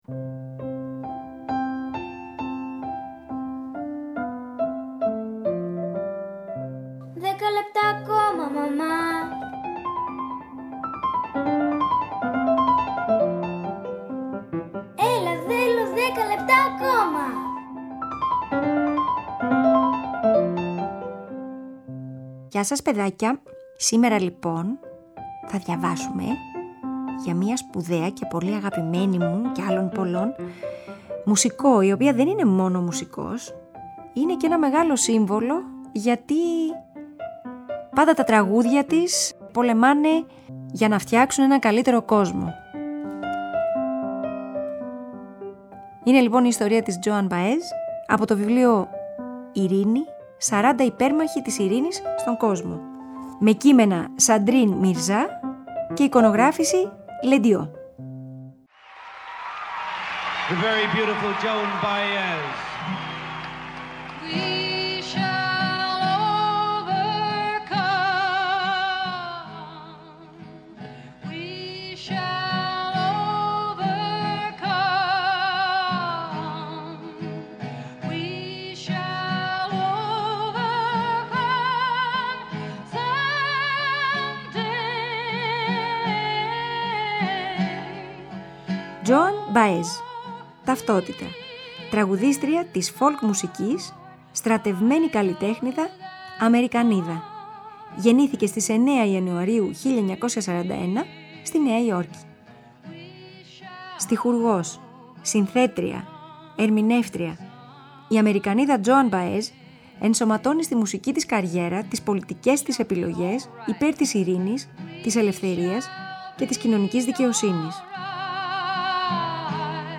Σήμερα θα διαβάσουμε για την ζωή και το έργο μιας καλλιτέχνιδας, αγωνίστριας για την ειρήνη. Θα διαβάσουμε αλλά και θα ακούσουμε για την Τζόαν Μπαέζ
Joan Baez, We shall overcome/Here’s to you